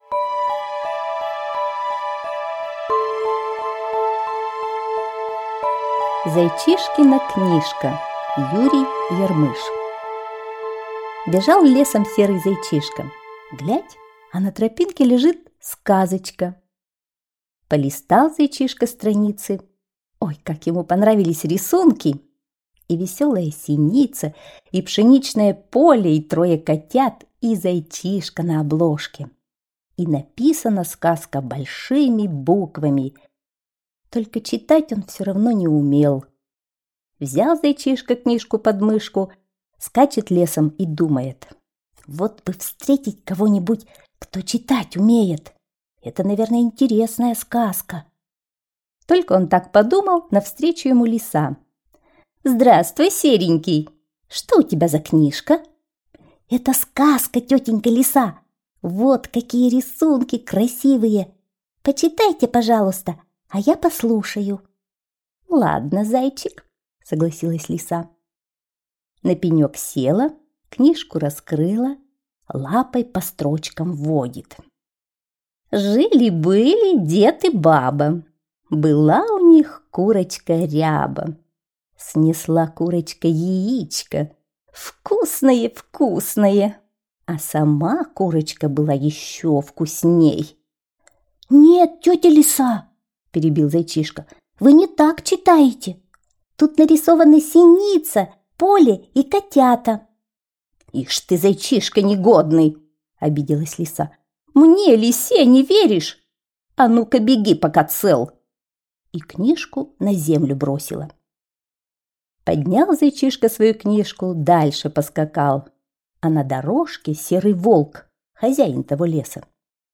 Аудиосказка «Зайчишкина книжка»